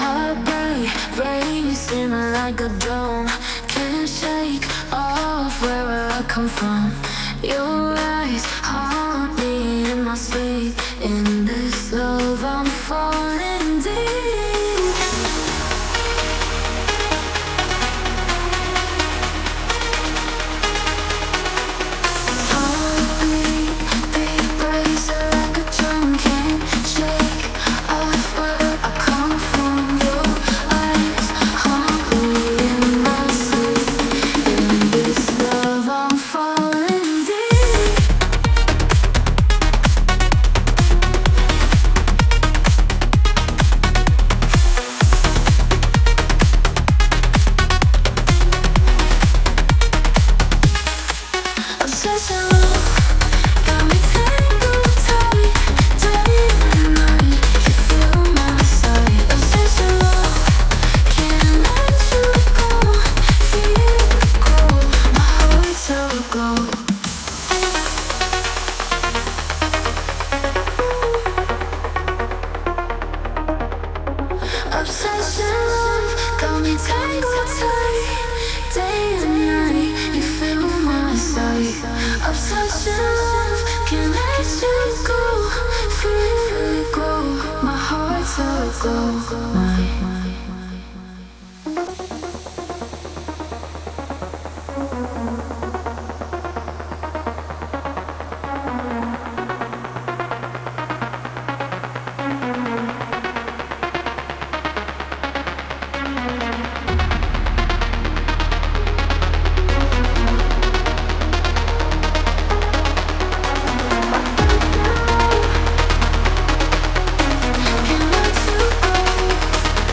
Ваша музыка созданная нейросетями